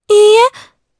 Laias-Vox-Deny_jp.wav